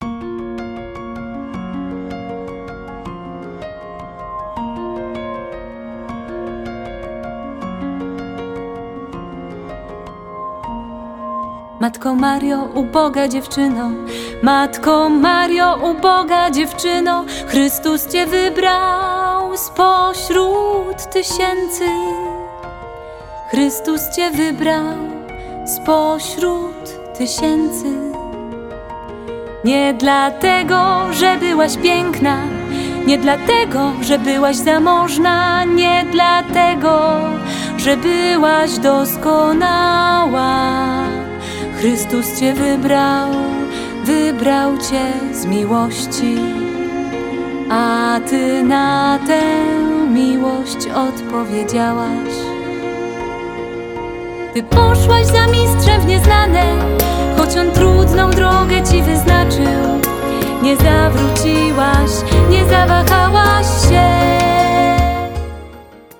ŚPIEW I CHÓRKI: